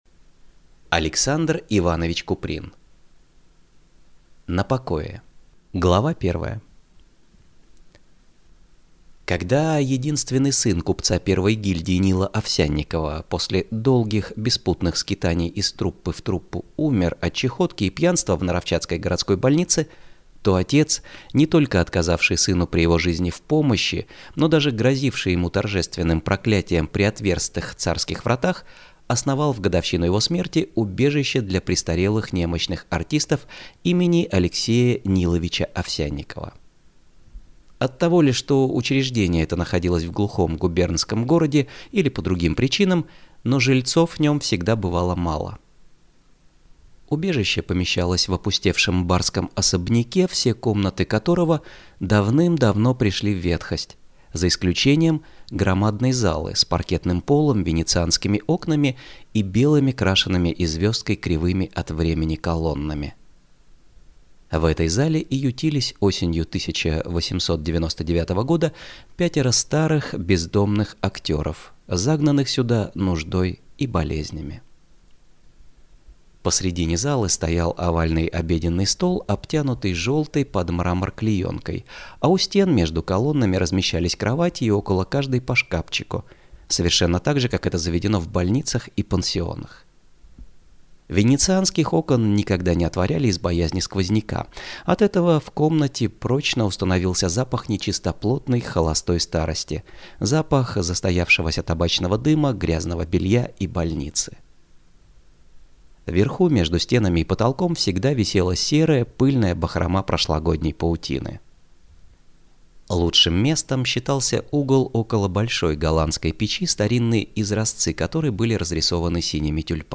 Aудиокнига На покое Автор Александр Куприн